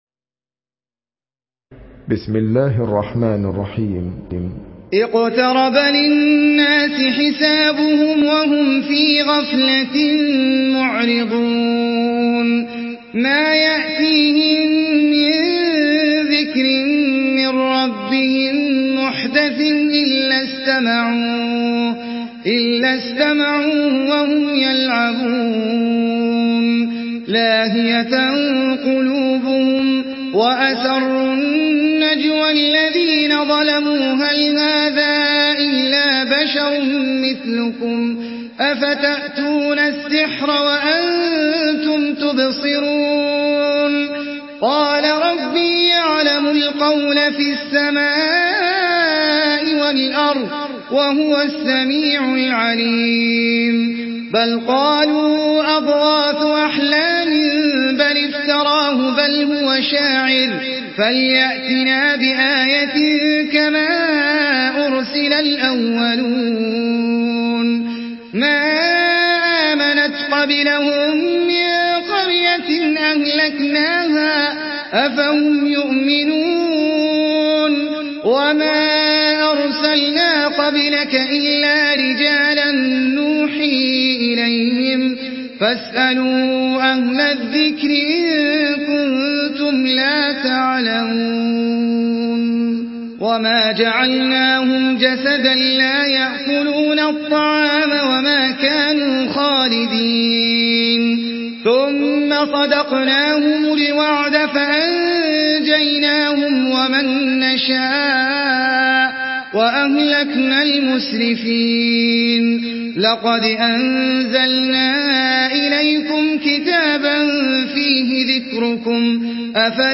Surah আল-আম্বিয়া MP3 by Ahmed Al Ajmi in Hafs An Asim narration.
Murattal Hafs An Asim